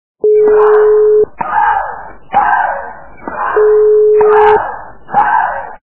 » Звуки » Люди фразы » Мария Шарапова - Тенис